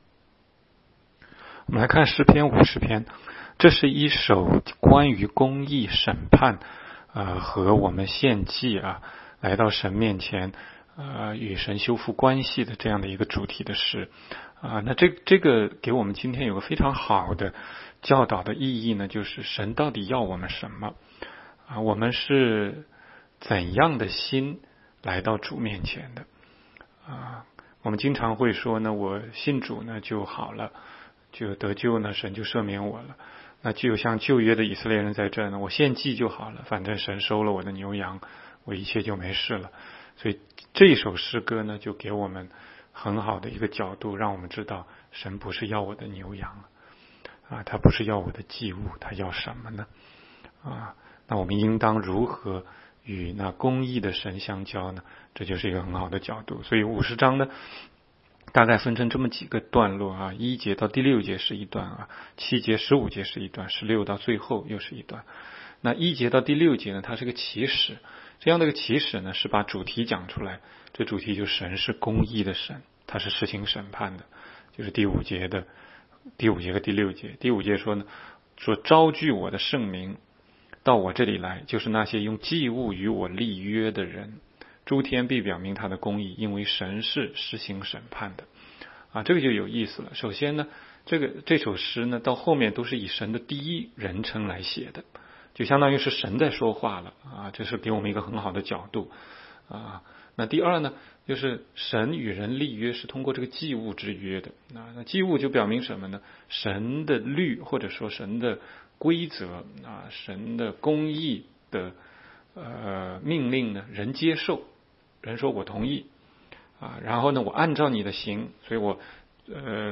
16街讲道录音 - 每日读经-《诗篇》50章